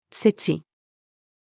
世知-female.mp3